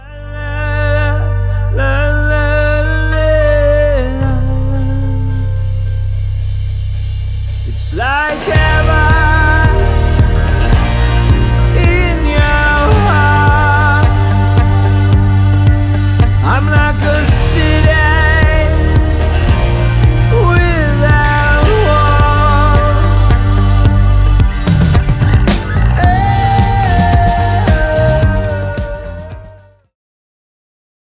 blending atmospheric rock and powerful lyrics.